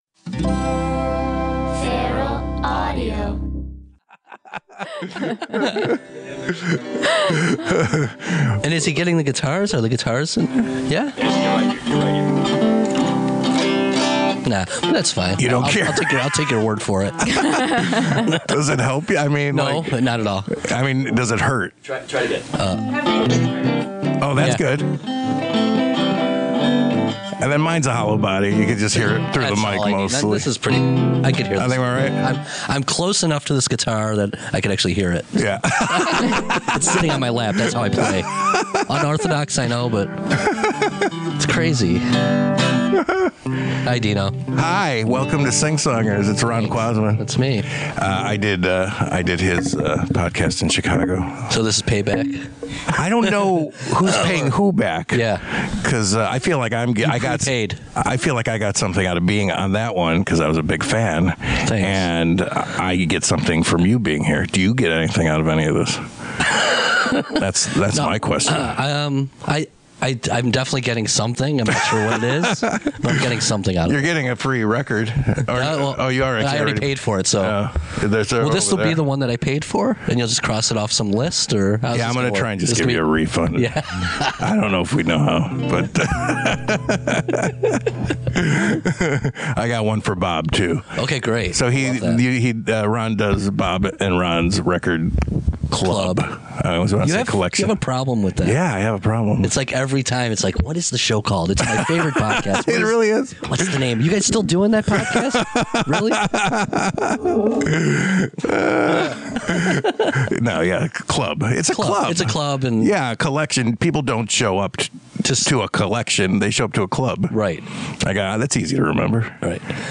The songwriter gets all the credit, but in the background the other band members add their own parts, working the melodies, finding the counter melodies, and building the song with each new take.